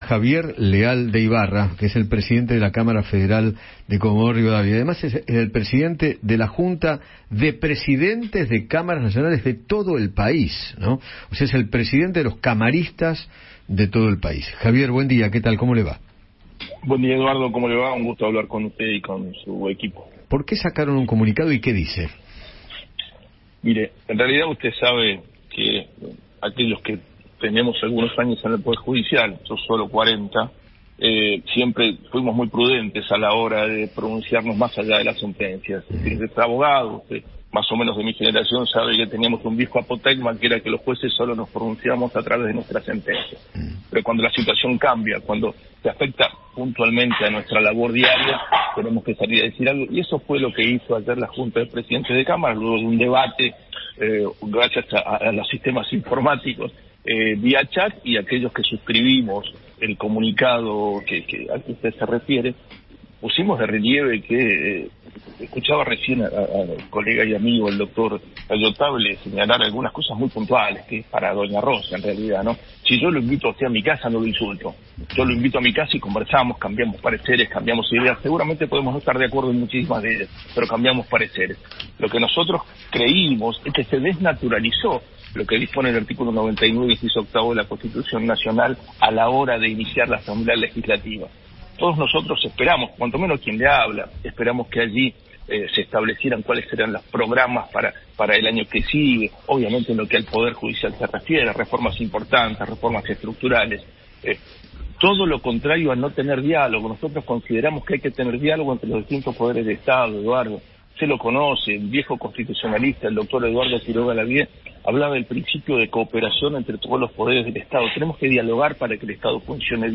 Javier Leal de Ibarra, Presidente de la Cámara Federal de Comodoro Rivadavia, dialogó con Eduardo Feinmann sobre el comunicado que hicieron tanto la Junta de Presidentes de las Cámaras Nacionales y Federales como la Asociación de Fiscales en repudio a los dichos del presidente.